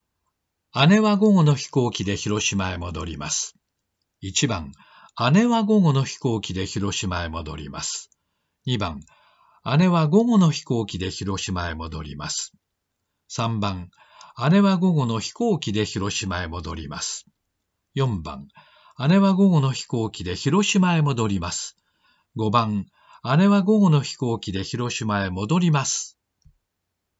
次に、「姉は」にプロミネンス（上昇の幅は任意である。以下同様）を付与した場合。（音声ファイルの1番）
「午後の」にプロミネンスを付与した場合。（音声ファイルの2番）
「飛行機で」にプロミネンスを付与した場合。（音声ファイルの3番）
「広島へ」にプロミネンスを付与した場合。（音声ファイルの4番）
「戻ります」にプロミネンスを付与した場合。（音声ファイルの5番）